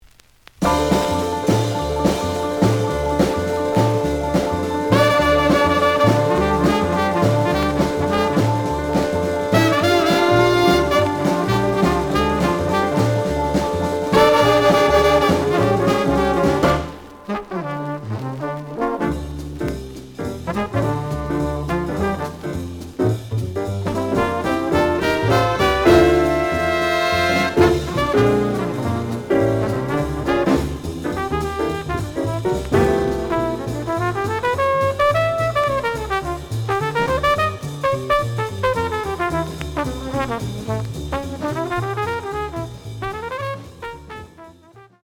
The audio sample is recorded from the actual item.
●Genre: Hard Bop